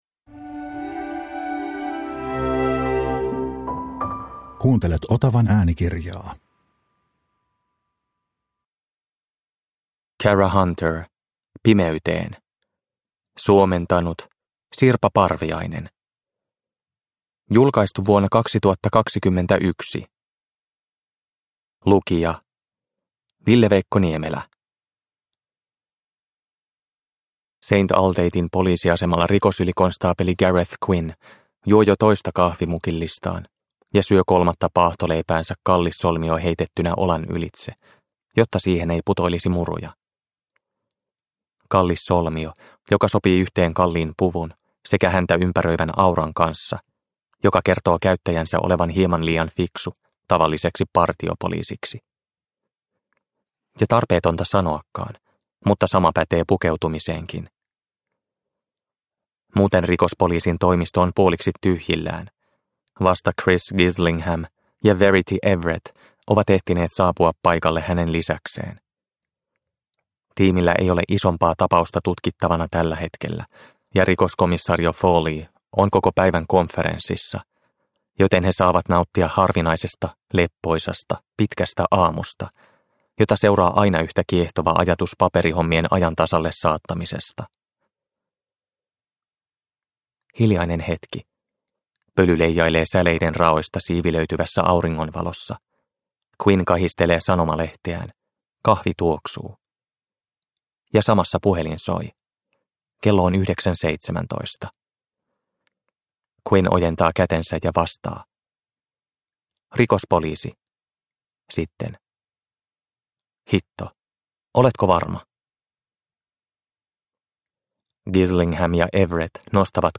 Pimeyteen – Ljudbok – Laddas ner